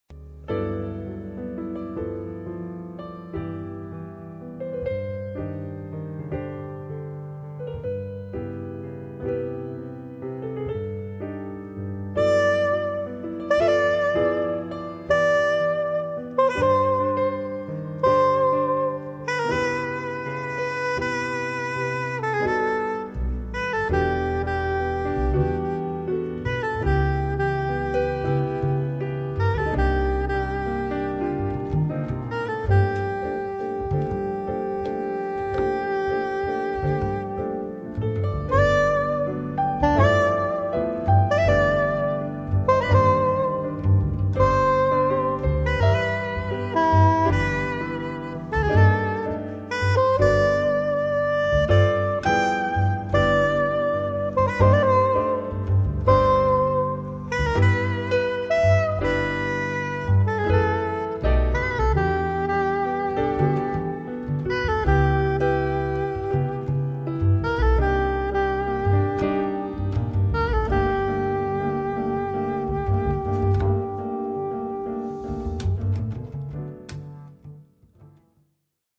sax tenore & soprano
pianoforte
contrabbasso